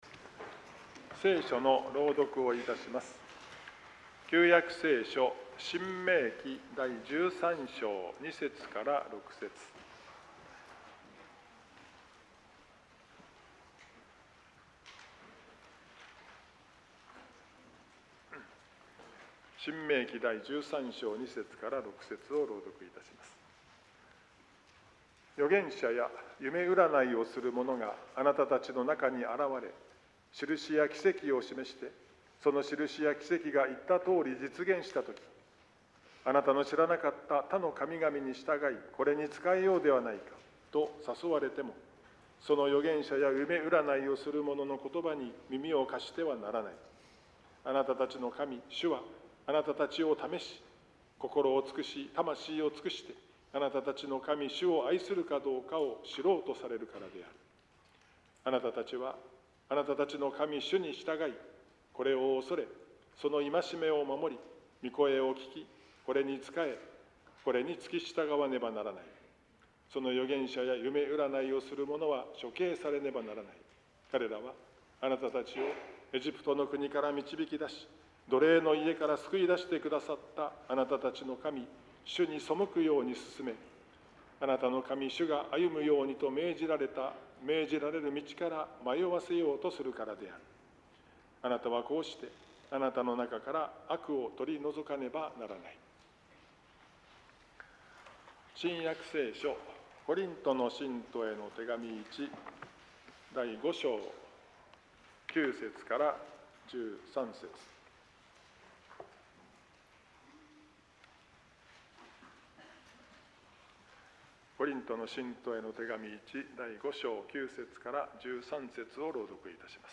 説教題「教会を建てる」